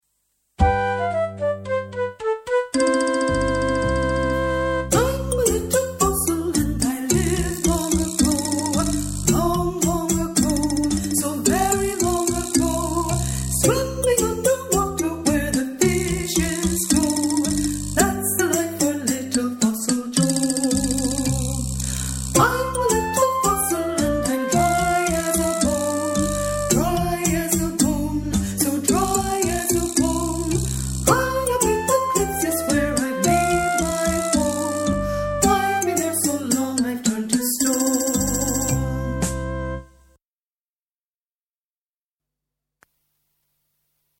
Fun-time songs for young children
Vocal, Piano, ideas for un-tuned percussion,
mp3 backing tracks downloads